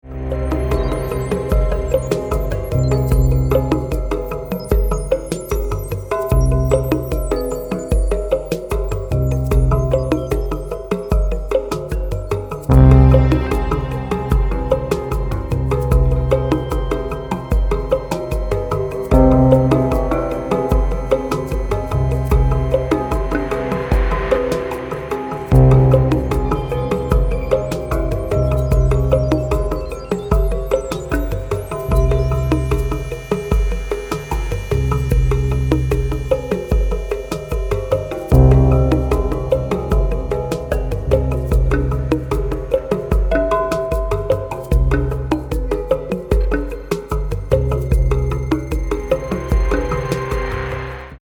classic Buchla modular synthesiser
Electronix Ambient